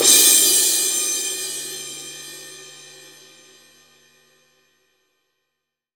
20 CRASH.wav